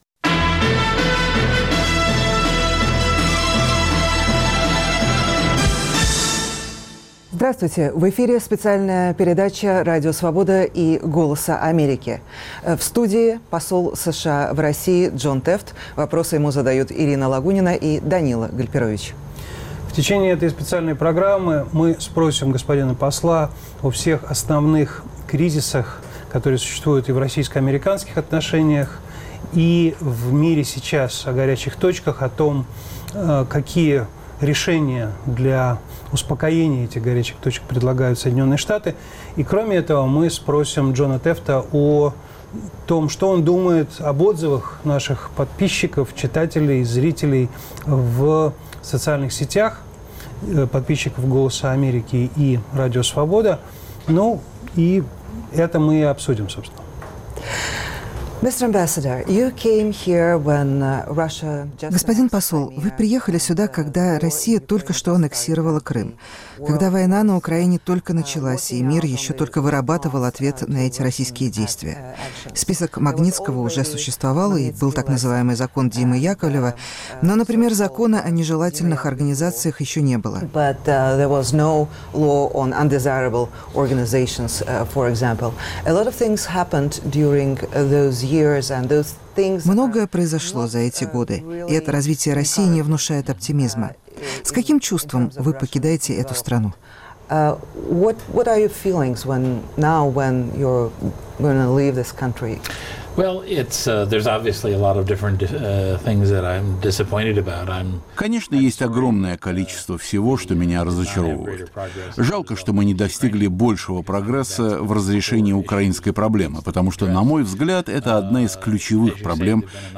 Специальная передача - интервью Радио Свобода и "Голоса Америки" с послом США в РФ Джоном Теффтом